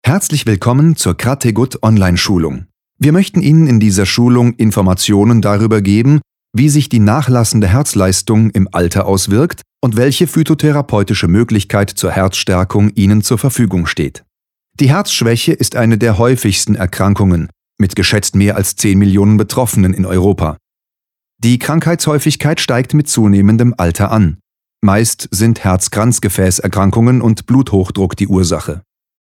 deutscher Profi - Sprecher.
Sprechprobe: eLearning (Muttersprache):
In one line I can describe my voice as: male, sympathetic and very flexible.